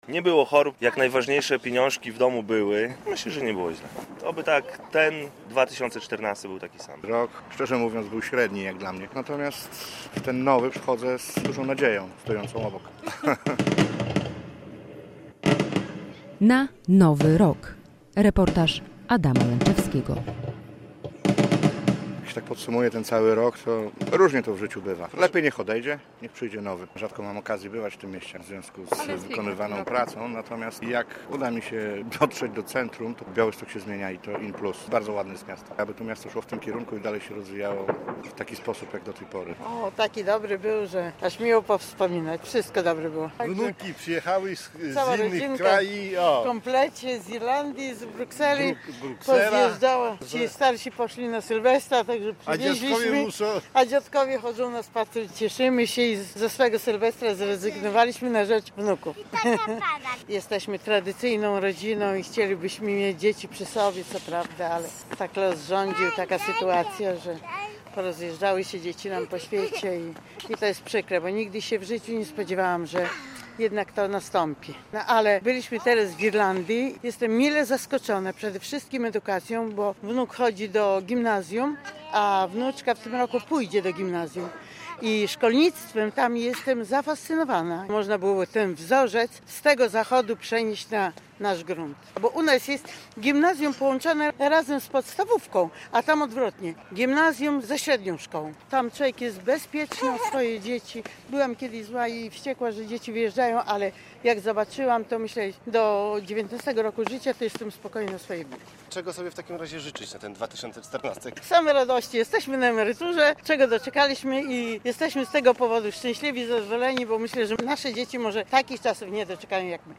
Kilka tysięcy osób żegnało stary i witało nowy 2014 rok na Rynku Kościuszki w Białymstoku.